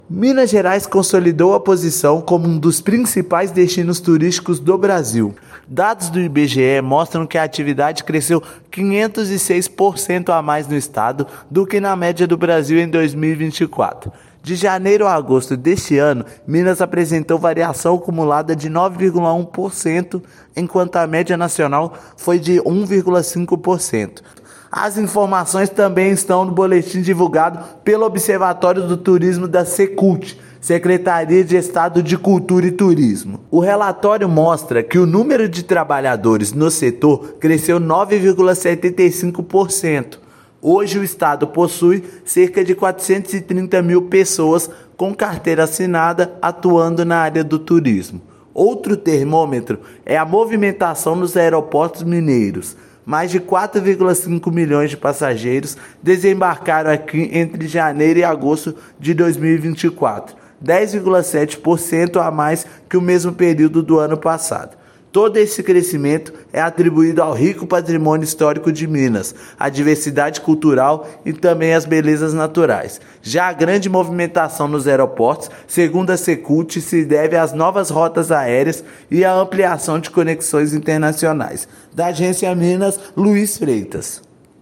Indicadores do IBGE consolidam o desenvolvimento do segmento no estado, referência nos últimos anos no Brasil. Ouça matéria de rádio.